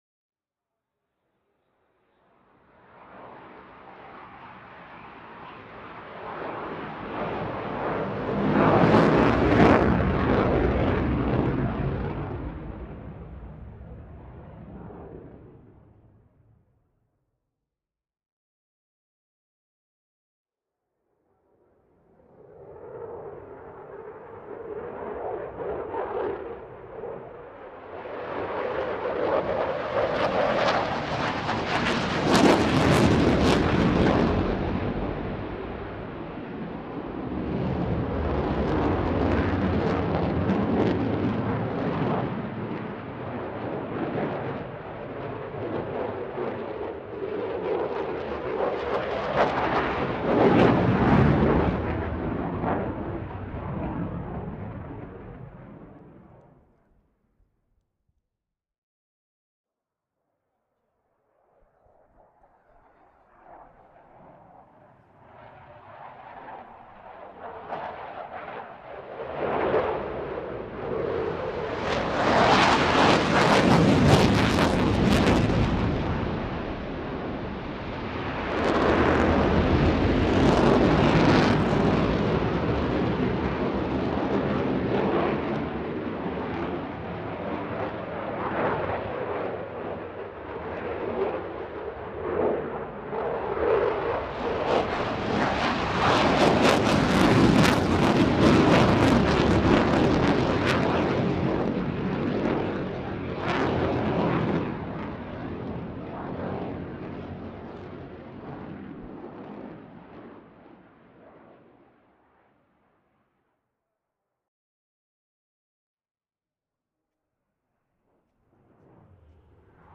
Eurofighter | Sneak On The Lot
Airplane Eurofighter Typhoon flyby with air distortion jet